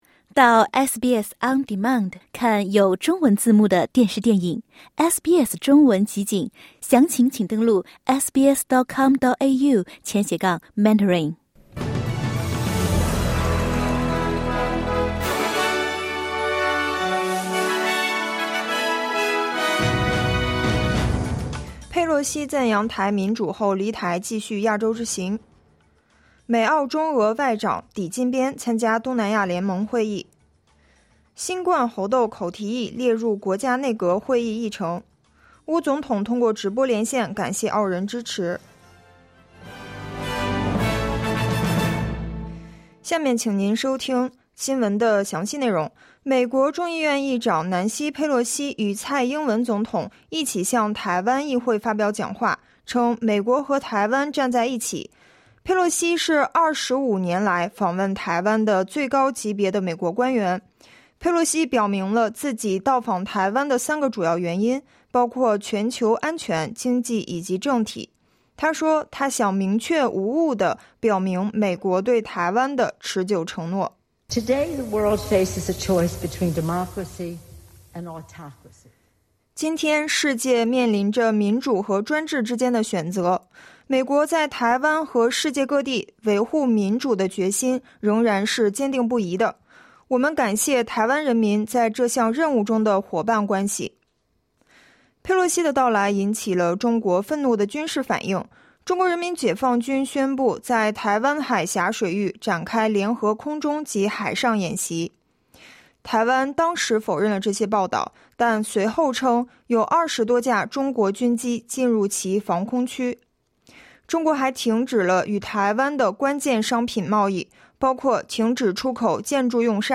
SBS早新闻（8月4日）